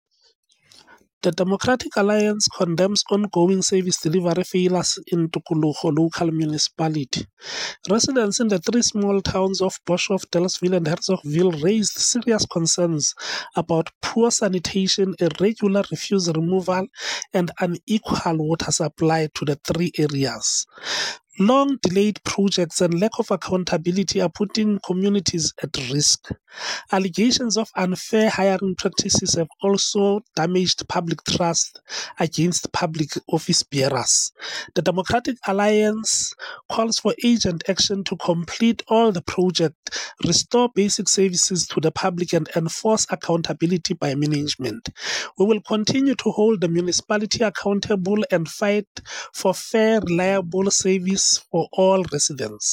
Sesotho soundbites by Cllr Hismajesty Maqhubu.